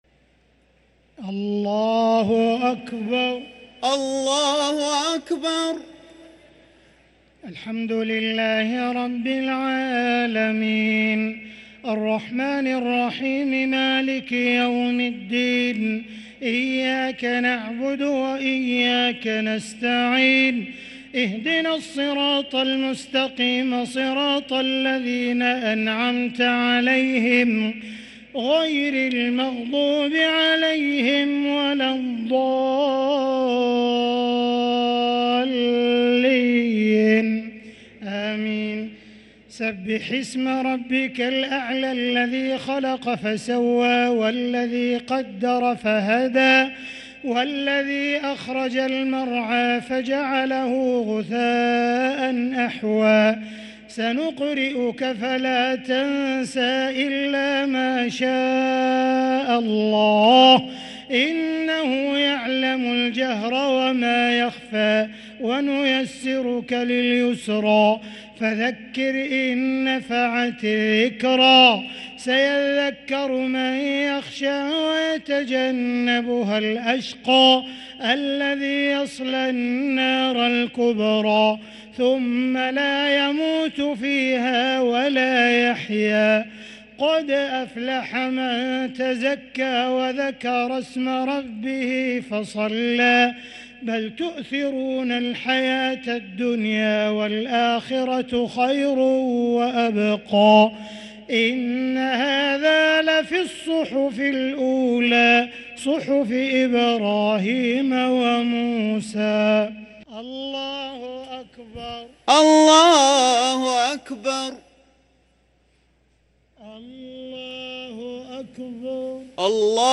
الشفع و الوتر ليلة 25 رمضان 1444هـ | Witr 25 st night Ramadan 1444H > تراويح الحرم المكي عام 1444 🕋 > التراويح - تلاوات الحرمين